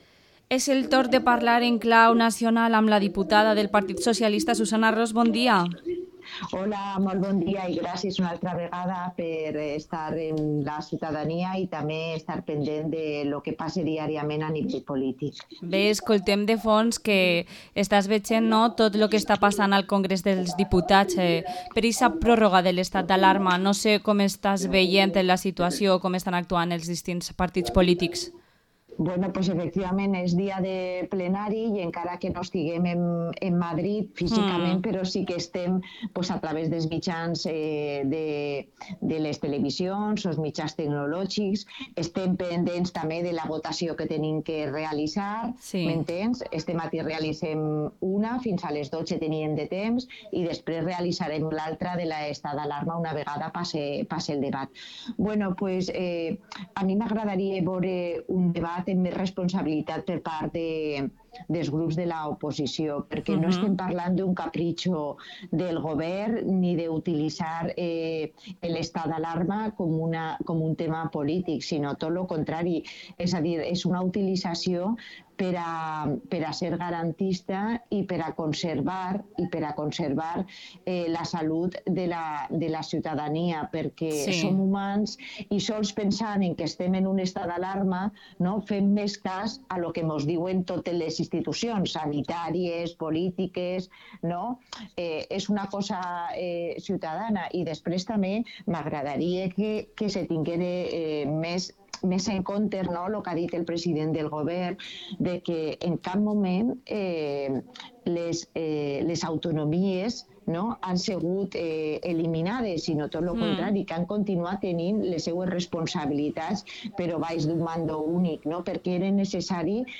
Entrevista a la diputada nacional del PSOE, Susana Ros